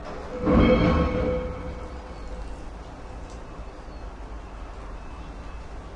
描述：正在努力上传我的索尼M10的录音，抱歉一直在重复。来自附近的火车厂，重金属物体被扔进金属容器/跳板/垃圾箱的声音。
Tag: 崩溃 工厂 金属 噪声 工业